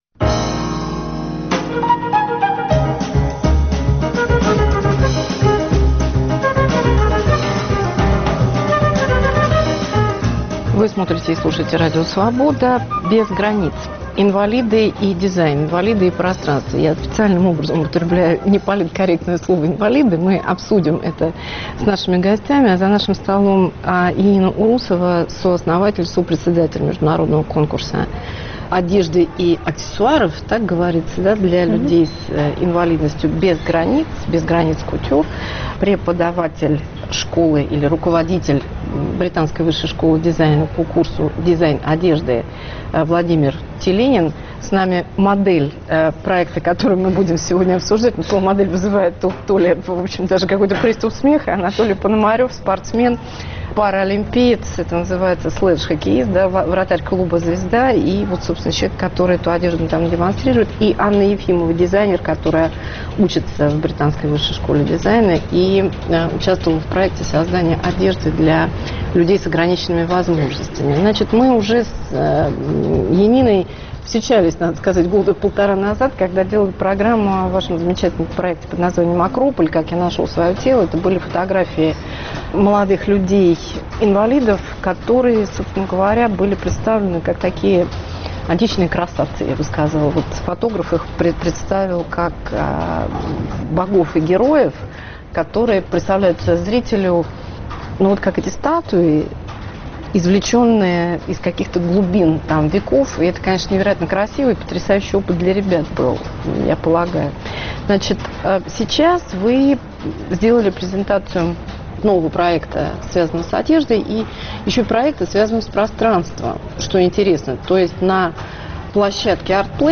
Об одежде и пространстве для инвалидов – с инвалидами и дизайнерами. Что должен знать дизайнер одежды для инвалидов: медицинские, психологические, инженерные, химические, биологические аспекты. Эмпатия в работе дизайнера с инвалидами. Индустрия и рынок потребления инвалидности в мире и в России.